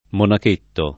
monachetto [ monak % tto ]